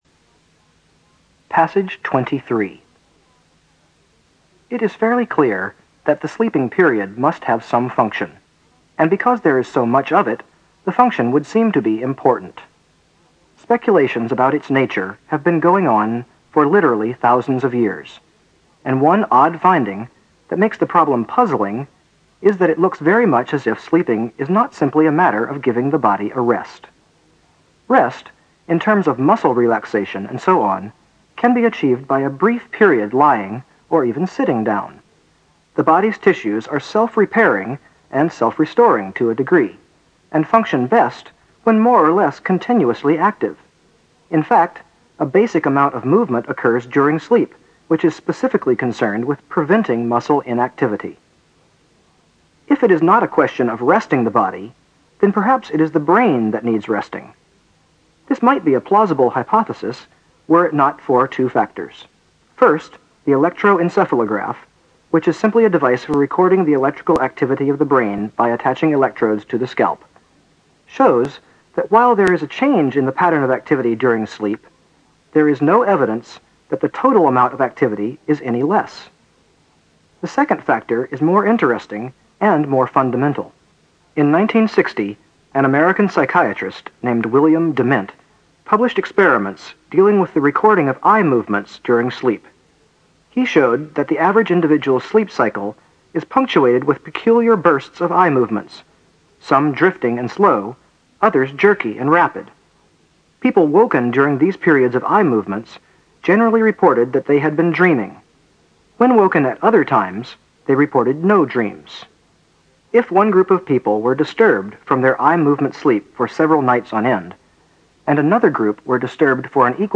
新概念英语85年上外美音版第四册 第23课 听力文件下载—在线英语听力室